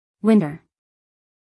winter-us-female.mp3